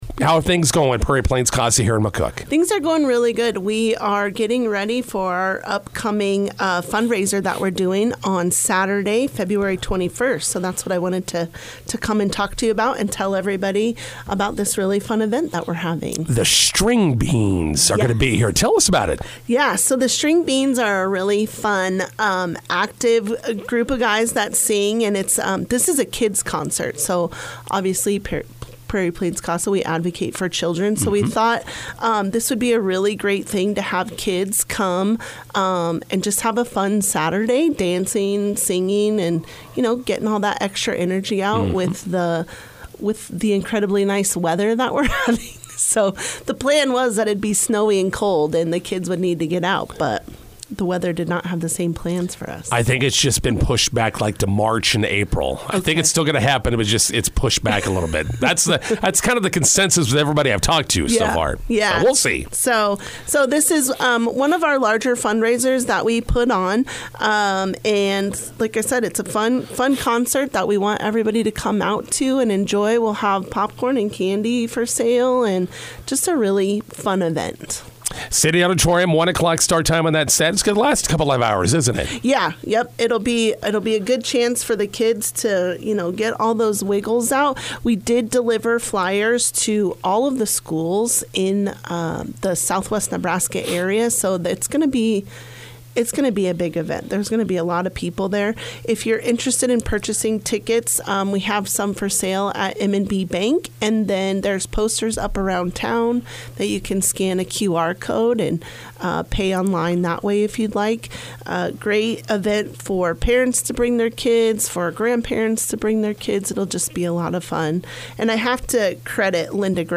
INTERVIEW: Prairie Plains CASA bringing "The String Beans" to McCook on Feb. 21st.